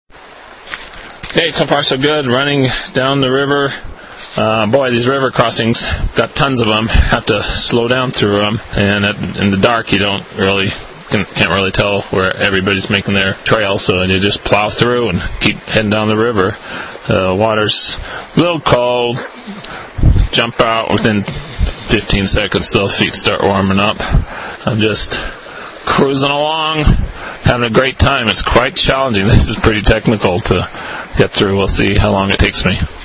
I would hear the sounds of the river for hours.
audio clip around mile 2.